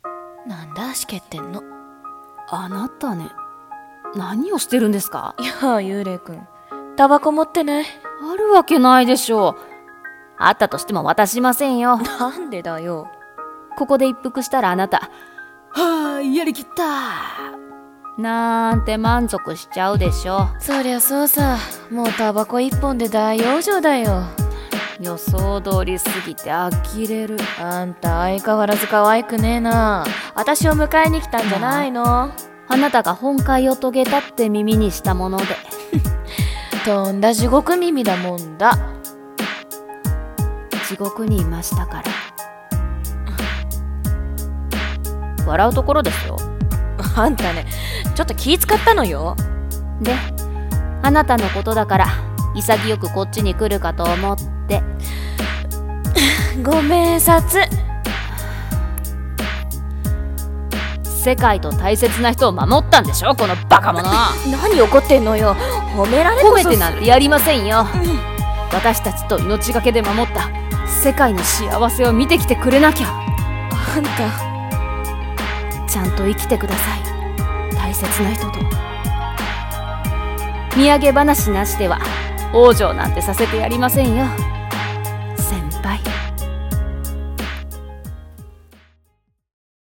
【二人台本】今際にて待つ【声劇台本】